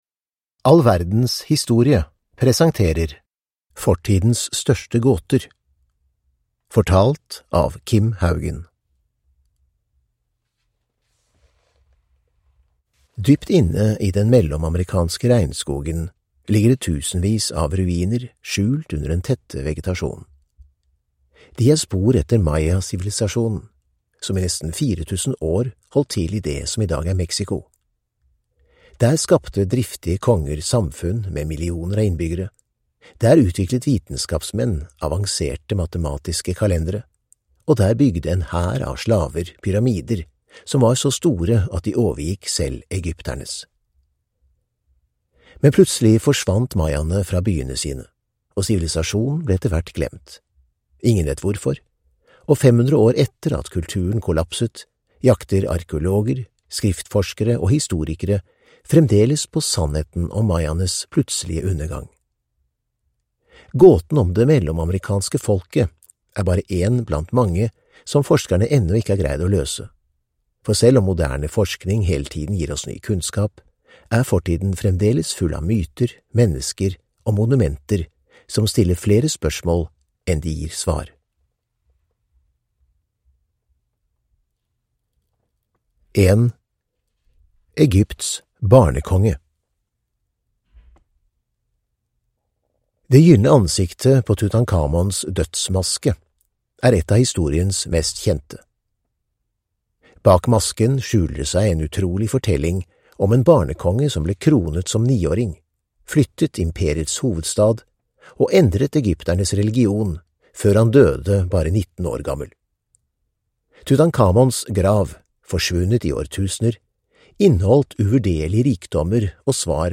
Fortidens største gåter (ljudbok) av All verdens historie